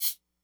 percussion.wav